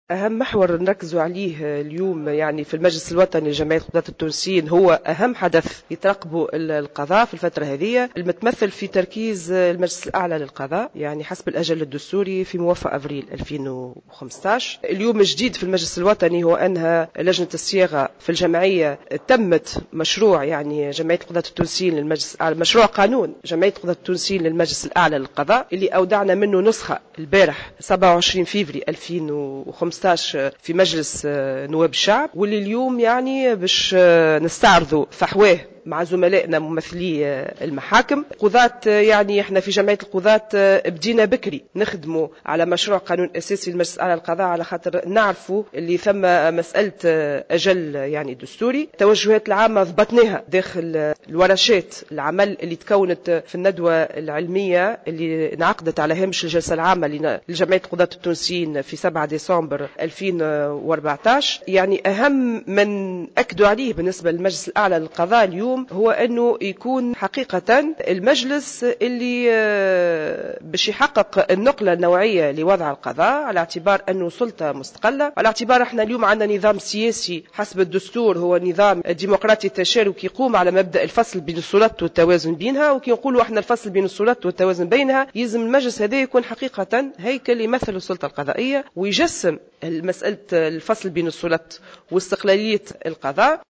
صرحت رئيسة جمعية القضاة التونسيين روضة القرافي اليوم السبت 28 فيفري 2015 لمراسلة جوهرة أف أم بأن الجمعية أنهت مشروعها بخصوص المجلس الأعلى للقضاء وأودعت أمس نسخة منه في مجلس نواب الشعب.